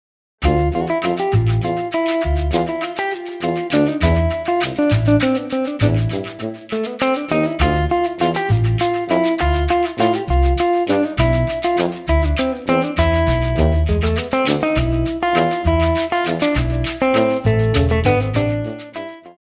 Ou encore ici, avec une phrase « ethno » en 7/4 en utilisant des « keyswitch » pour choisr les samples et le choix des cordes…: guitare-drums-2x tp// guitare_drums/ tp solo:
Certaines banques d’instruments virtuels offrent aussi la possibilité grâce un « script » de chevaucher les notes recréant aini un effet de liaison (ici sonorité de guitare jazz d’un relevé de morceau klezmer) :
klezmer_demo_key.mp3